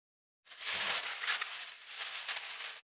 668コンビニ袋,ゴミ袋,スーパーの袋,袋,買い出しの音,ゴミ出しの音,袋を運ぶ音,
効果音